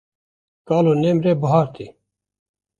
Pronúnciase como (IPA)
/bɪˈhɑːɾ/